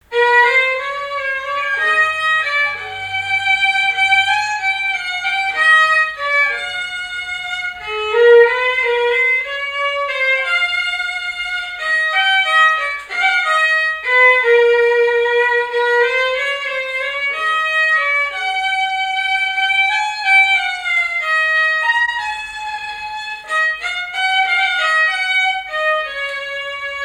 danse : valse
répertoire musical au violon
Pièce musicale inédite